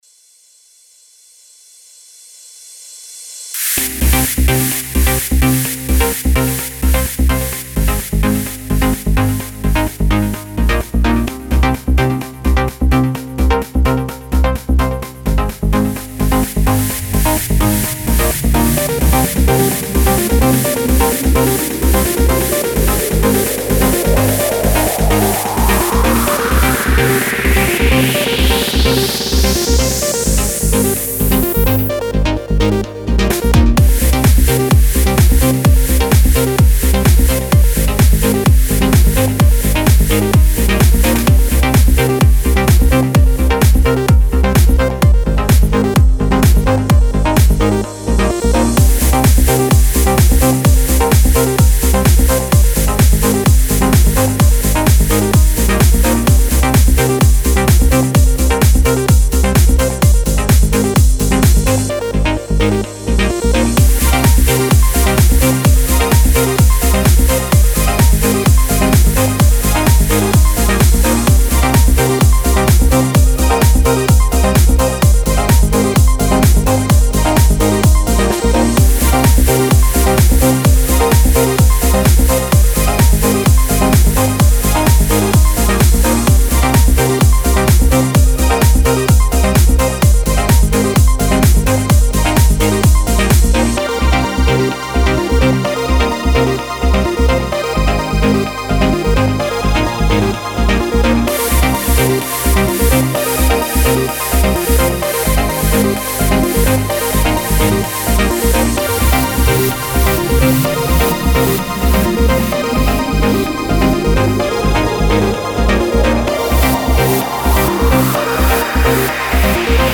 Minimal, Electronic, Jazz, Tech, Vintage, Oldschool - House